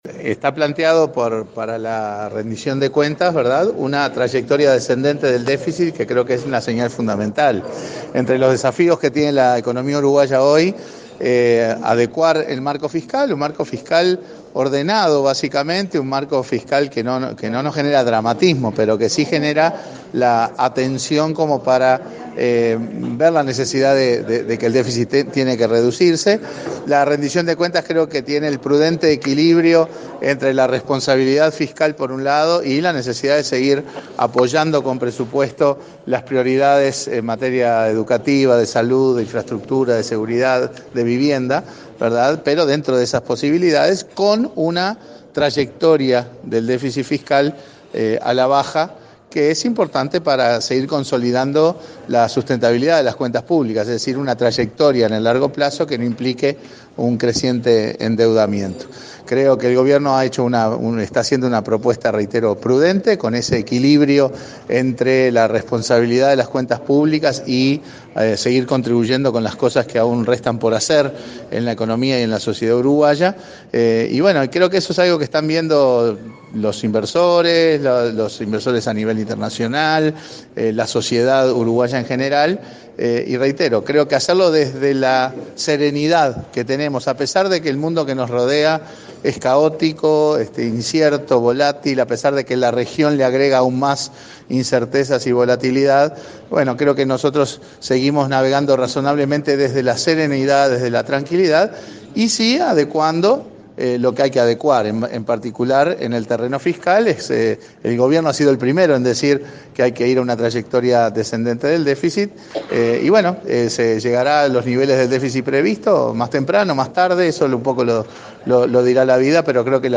El presidente del BCU, Mario Bergara, sostuvo que uno de los desafíos de la economía uruguaya es adecuar el déficit fiscal y, por ello, la Rendición de Cuentas tiene un prudente equilibrio. Al disertar en “Desayunos útiles” habló de la situación del dólar. A su juicio, lo más importante de lo que pasa en los países vecinos es el grado de incertidumbre, y eso es lo que hay que observar más que la fluctuación del tipo de cambio.